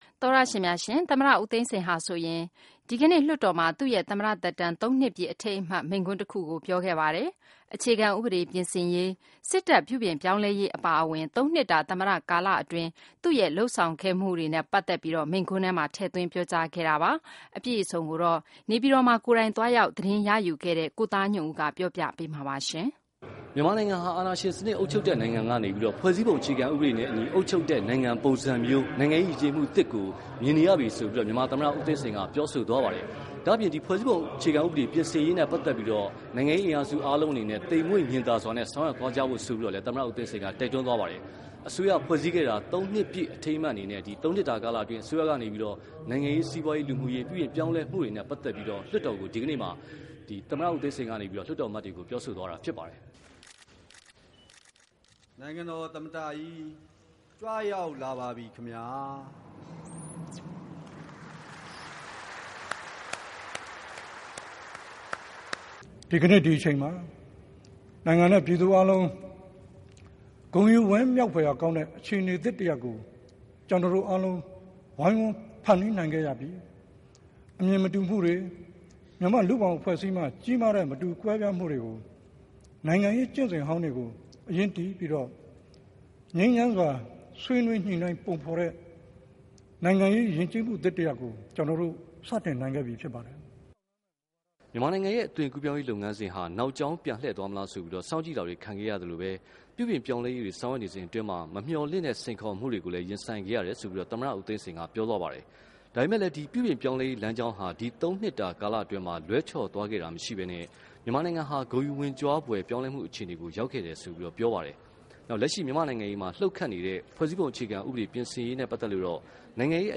မြန်မာသမ္မတဦးသိန်းစိန်မိန့်ခွန်း